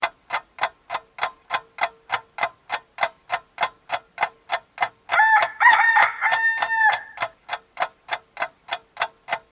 Рингтоны » для будильника » Часы с петухом